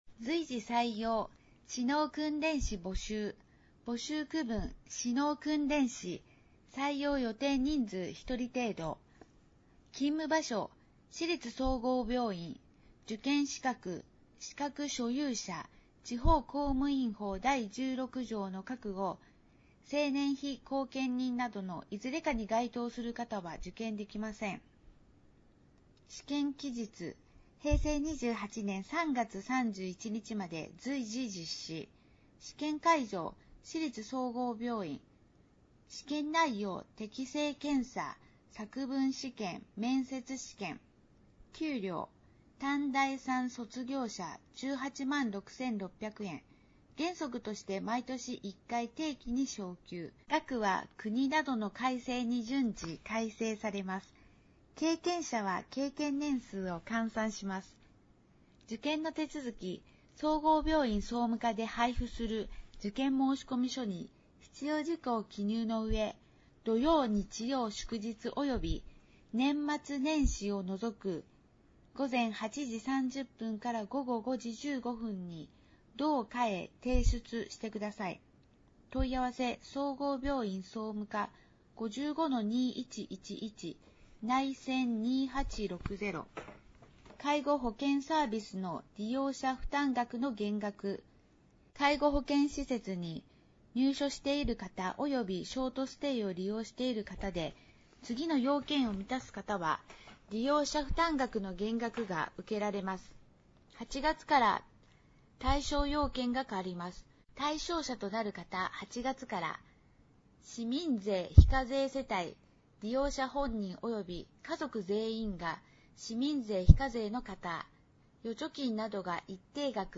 音声欄に表示があるものは、「声の広報」として、音声にてお聴きになれます。